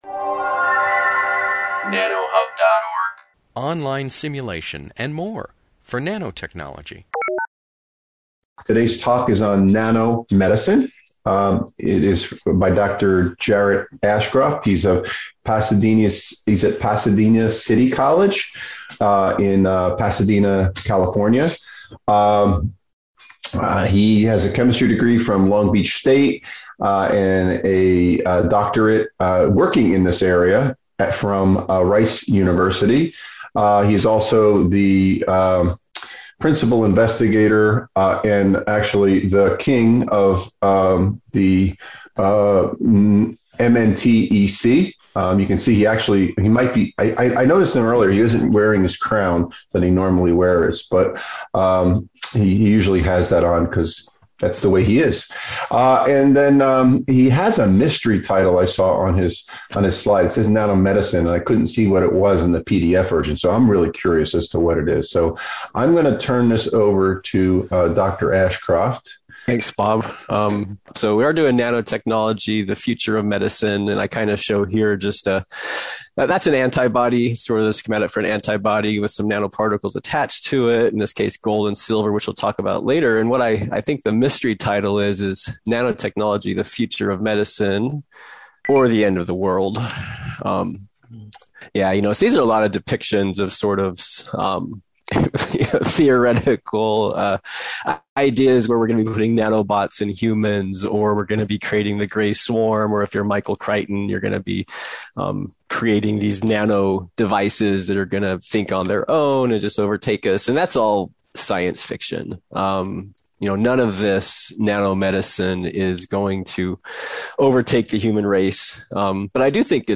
Instructional Material -- Lecture/Presentation